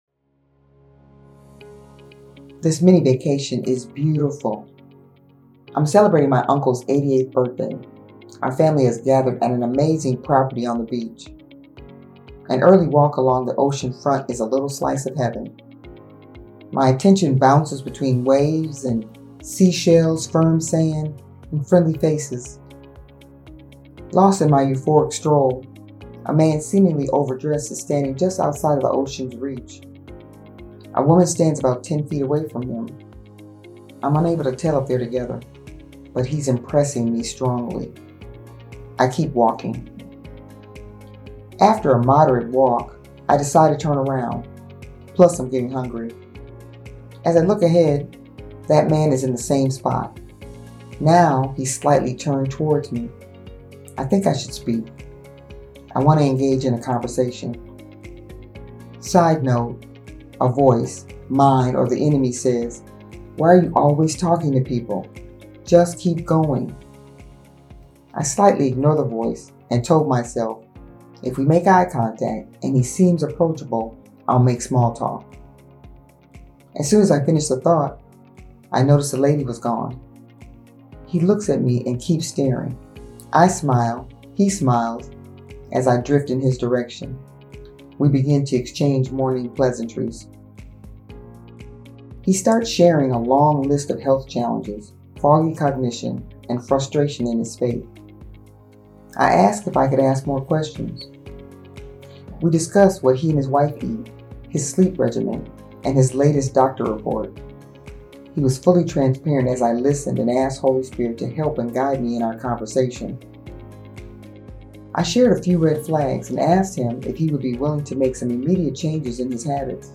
She shares words of inspiration, encouragement, and prayer.